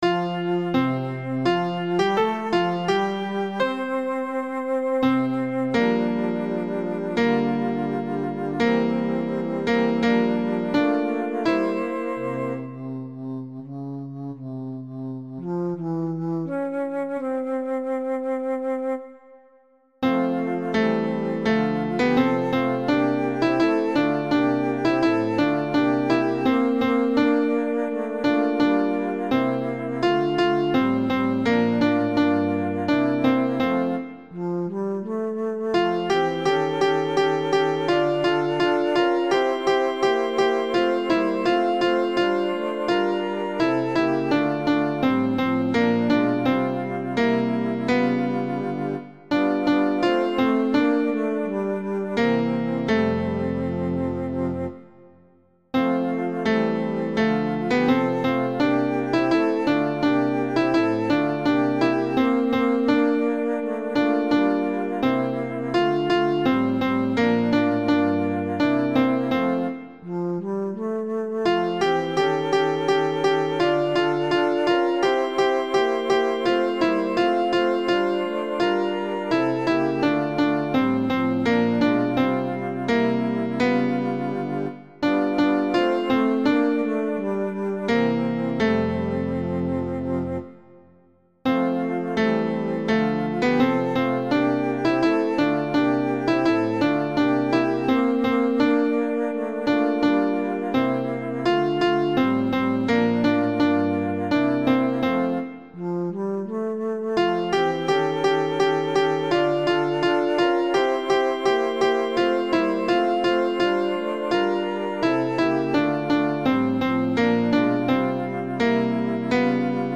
La-femme-du-guide-alto.mp3